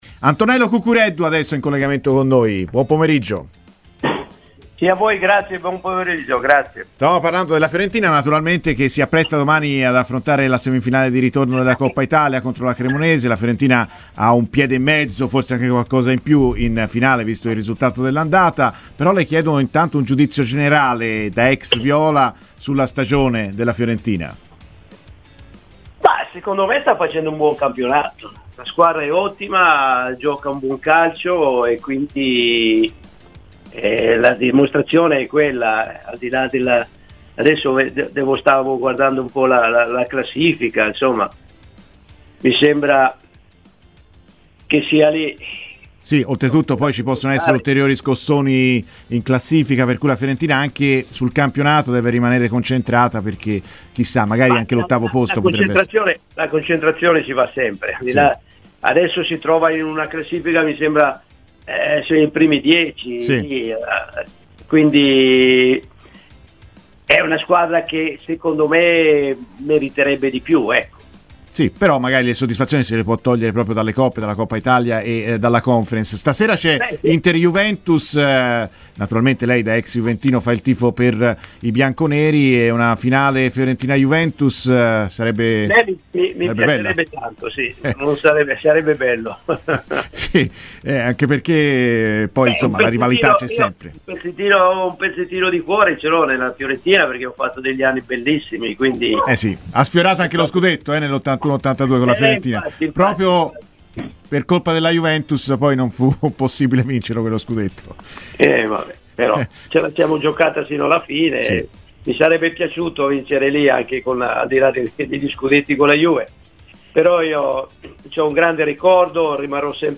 Intervistato ai microfoni di Radio FirenzeViola durante Viola AmoreMio, l'ex Fiorentina e Juventus Antonello Cuccureddu ha parlato così delle due semifinali di Coppa Italia in programma oggi e domani: "La Fiorentina sta facendo bene, anche se in campionato meriterebbe una posizione più prestigiosa.